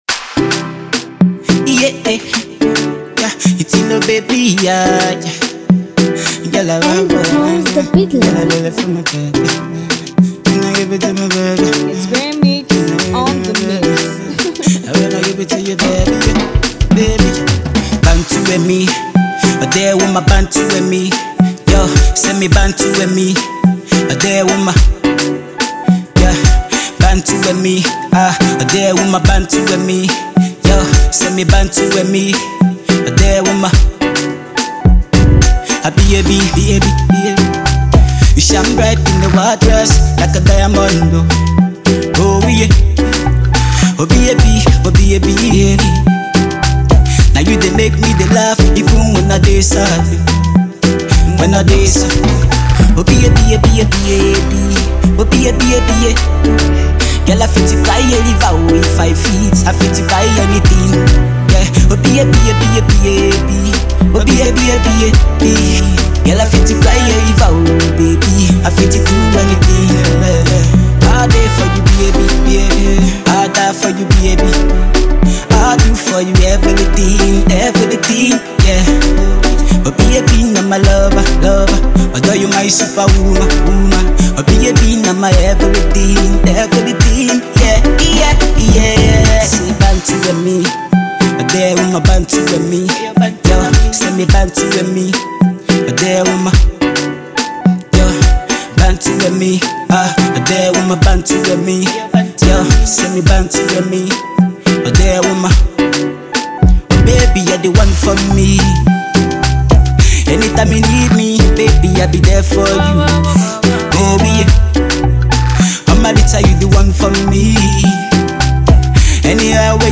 I'm an afro beat Singer and song writer